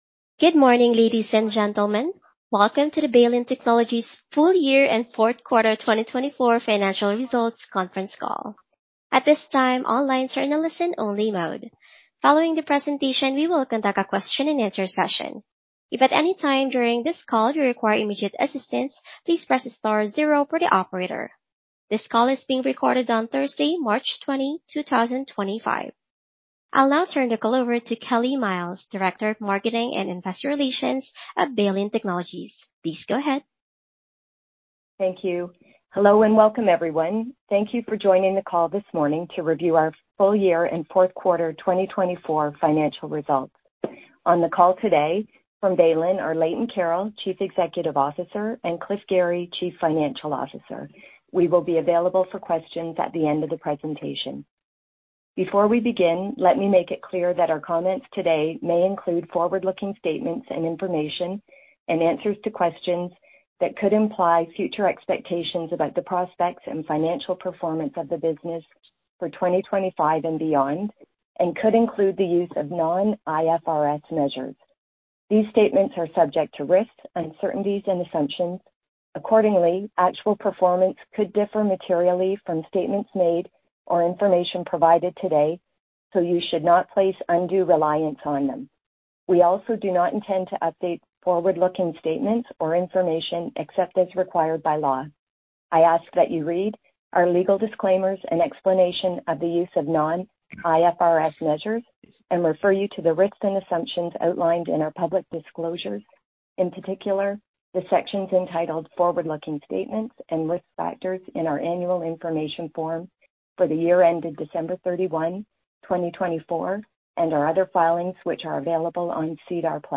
Full Year and Fourth Quarter 2024 Conference Call Thursday, March 20, 2025 10:00 A.M. ET